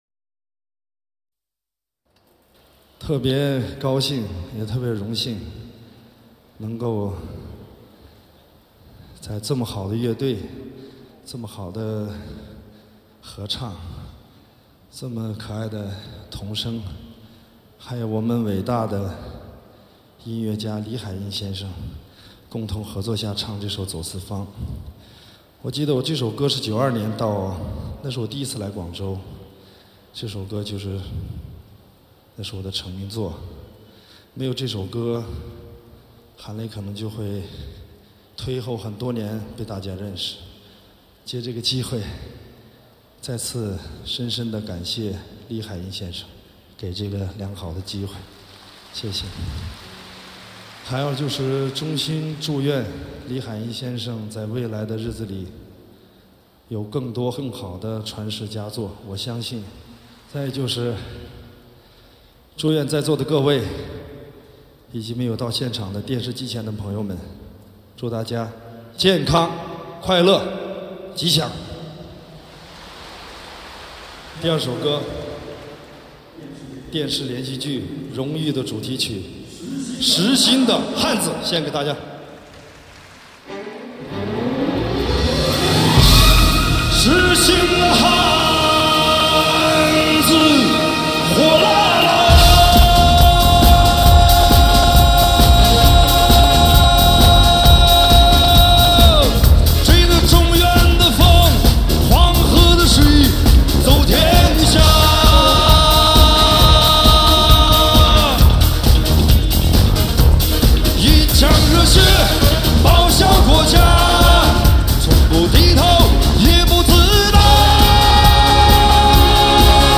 类型: HIFI试音
发烧演唱会录音，成就最具纪念意义和价值的珍藏。